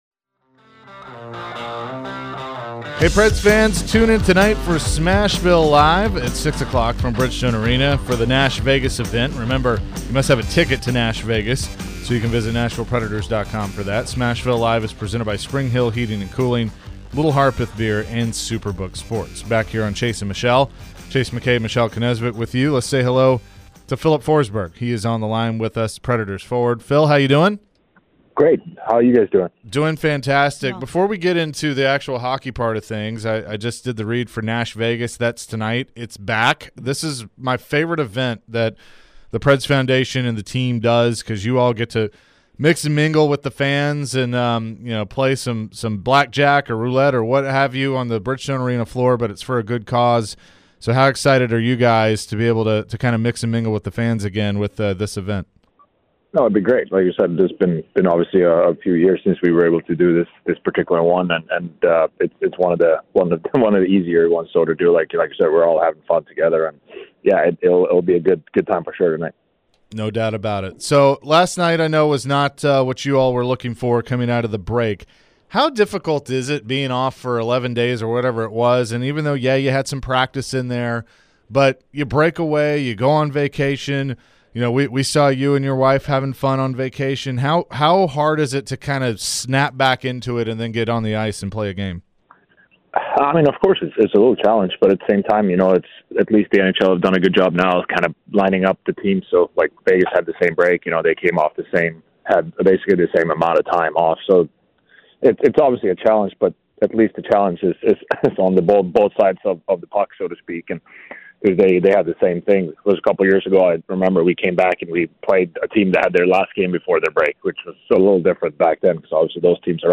Filip Forsberg Interview (2-8-23)